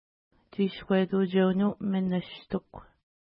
Pronunciation: tʃi:skwetu:tʃeunnu-ministuk
Pronunciation